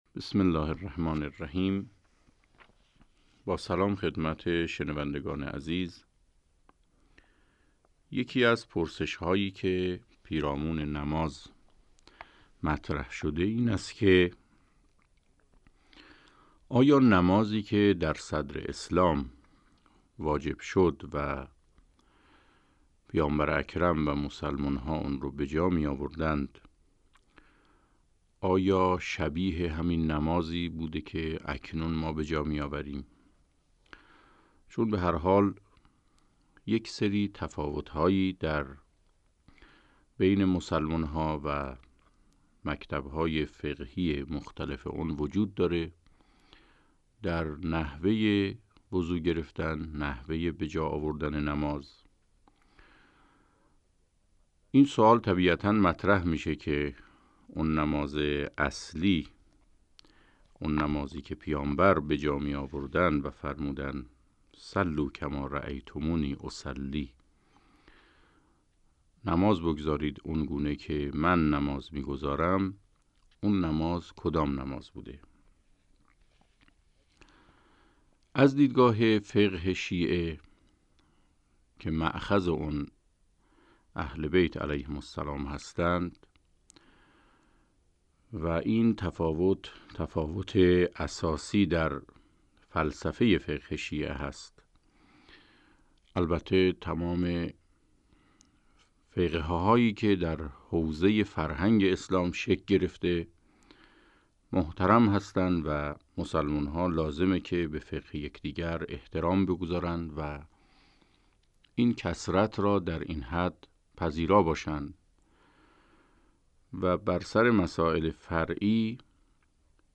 پرسش و پاسخ